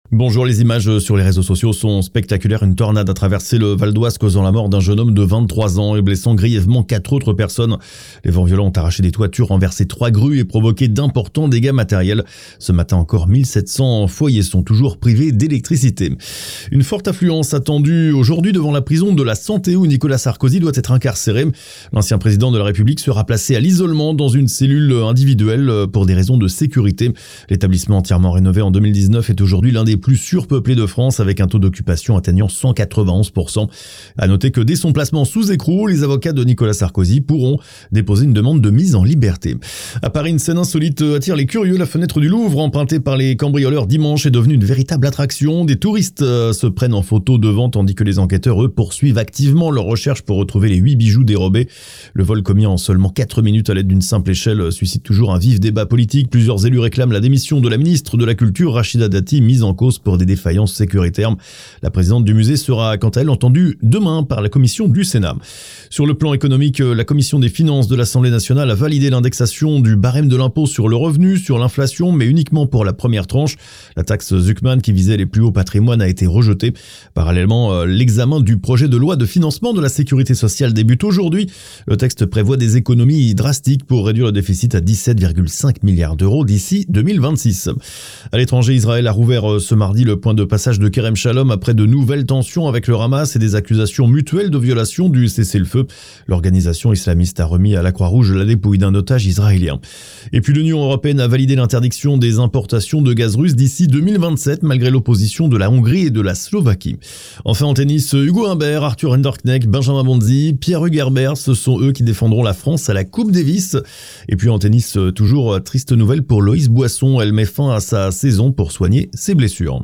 Flash infos 21/10/2024